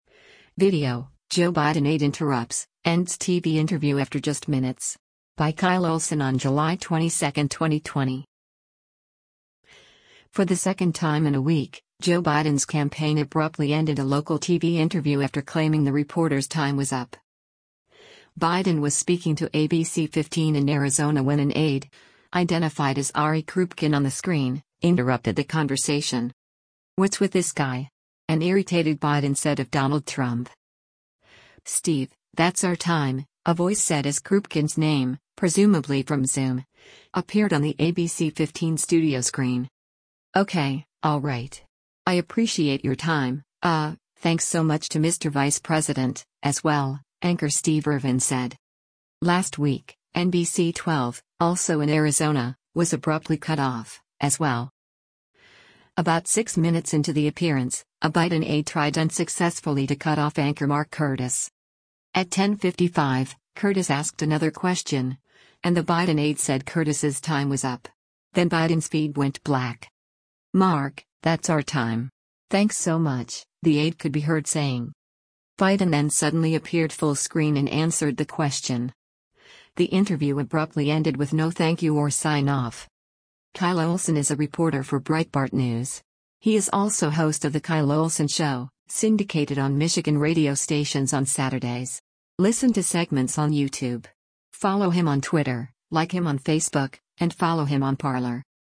Biden TV Interview Cut Off
“What’s with this guy?” an irritated Biden said of Donald Trump.